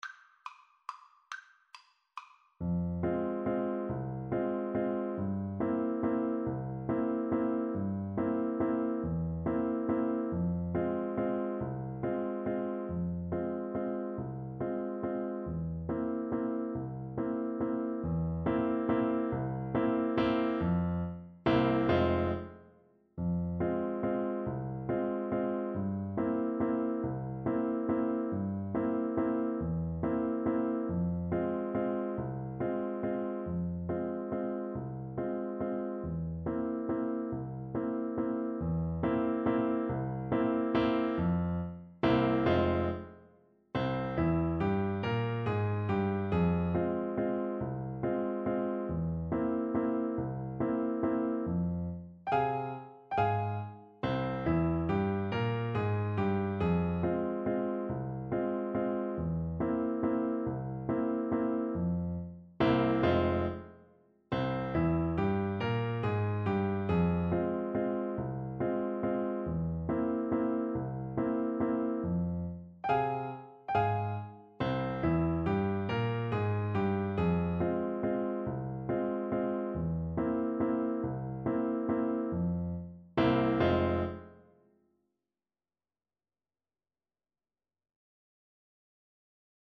3/4 (View more 3/4 Music)
Tempo di Valse =140
Classical (View more Classical Voice Music)